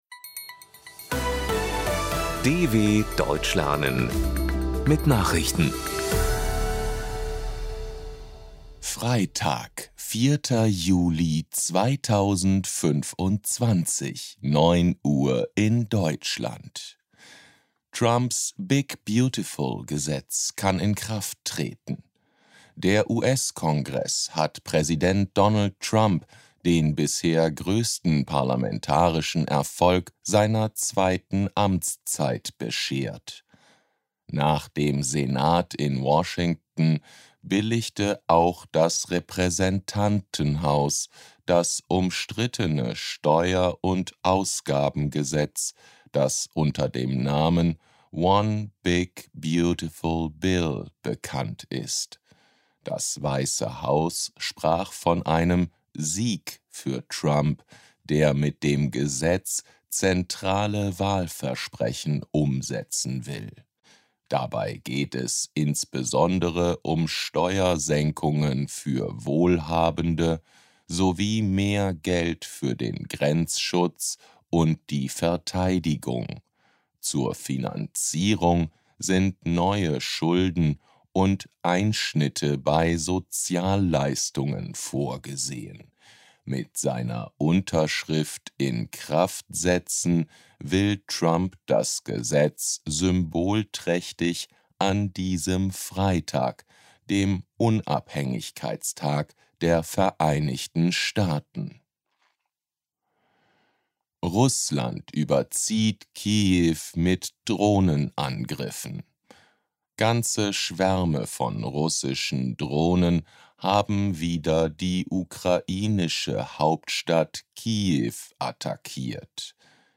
Langsam Gesprochene Nachrichten | Audios | DW Deutsch lernen
04.07.2025 – Langsam Gesprochene Nachrichten